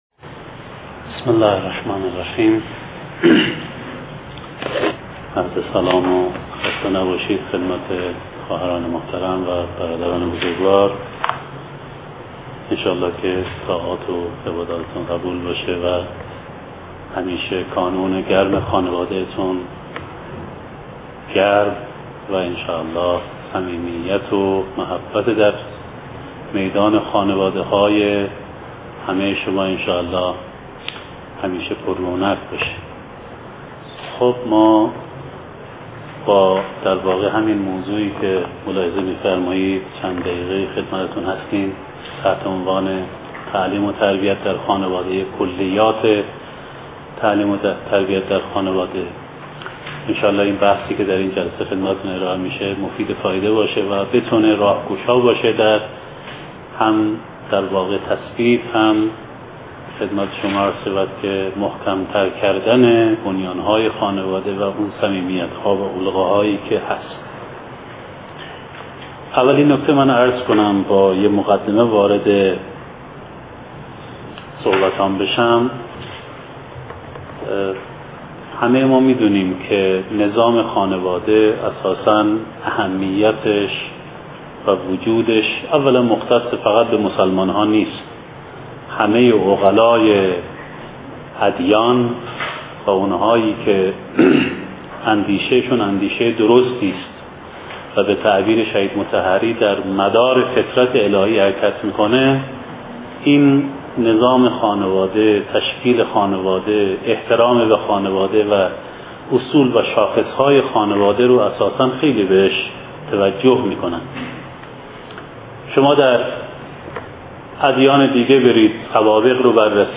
مسئول نهاد نمایندگی مقام معظم رهبری در دانشگاه، در اولین جلسه از دوره کوتاه‌مدت ارتقاء مدیریت نظام‌مند و کارآمد خانواده اسلامی-ایرانی، بر تدریجی بودن فرآیند تعلیم و تربیت تأکید کرد.